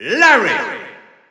Announcer pronouncing Larry in French.
Category:Bowser Jr. (SSBU) Category:Announcer calls (SSBU) You cannot overwrite this file.
Larry_French_Announcer_SSBU.wav